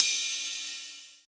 D2 RIDE-06.wav